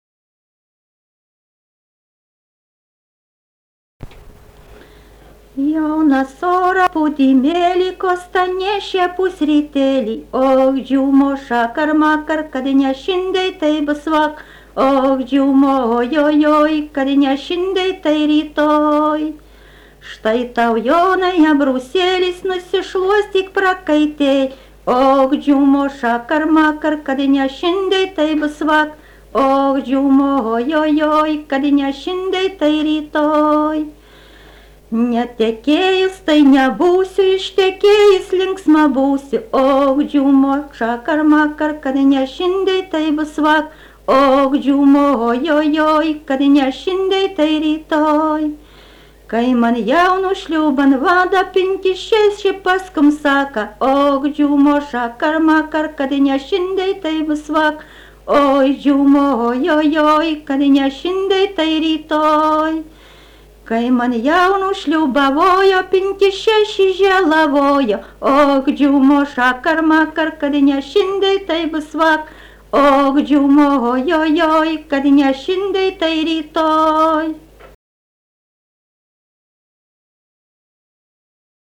daina
Šimonys
vokalinis